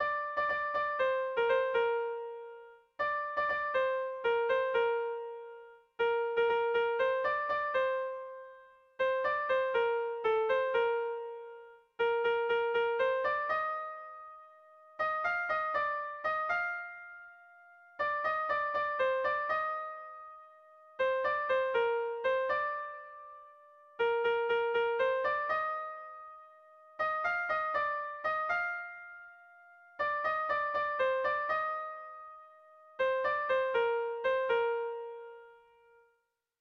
Sentimenduzkoa
ABDE